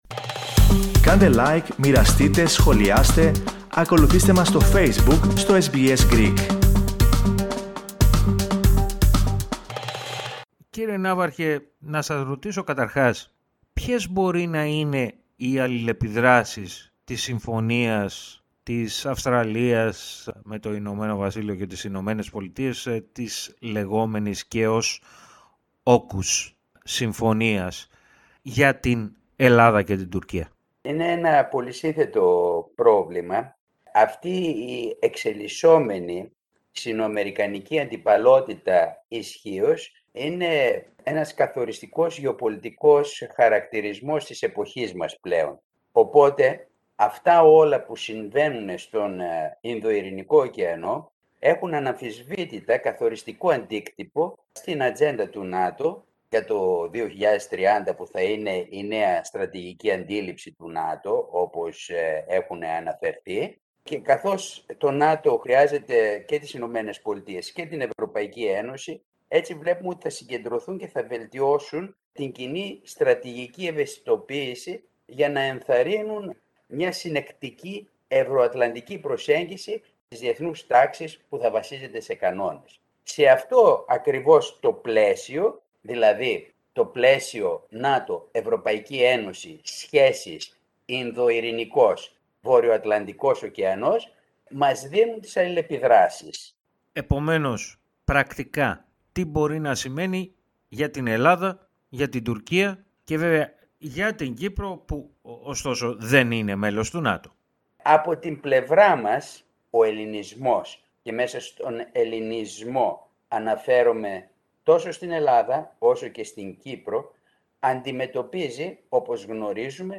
μίλησε στο Ελληνικό Πρόγραμμα της ραδιοφωνίας SBS.